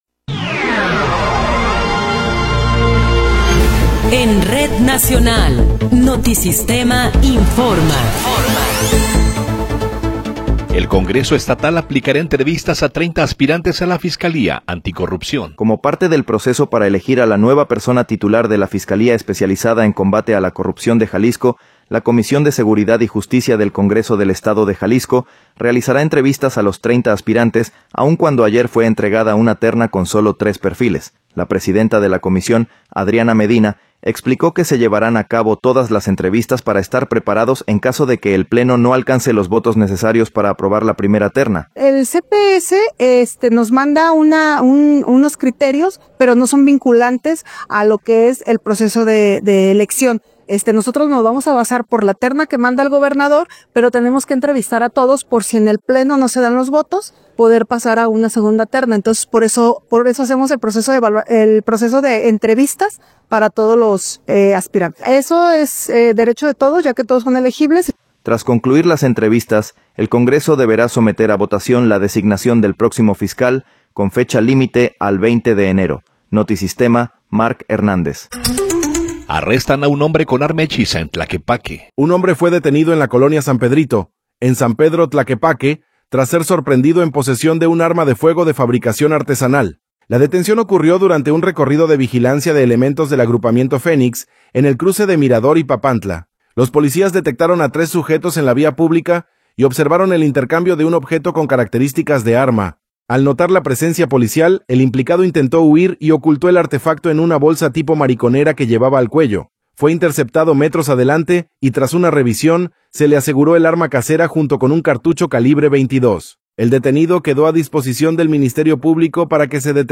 Noticiero 11 hrs. – 9 de Enero de 2026
Resumen informativo Notisistema, la mejor y más completa información cada hora en la hora.